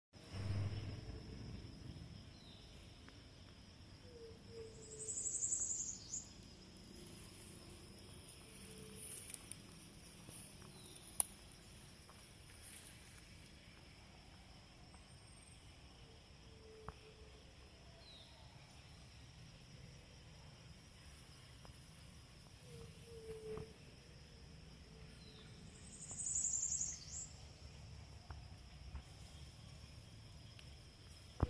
White-tipped Dove (Leptotila verreauxi)
Life Stage: Adult
Detailed location: Parque Municipal Vivero Cosme Argerich
Condition: Wild
Certainty: Recorded vocal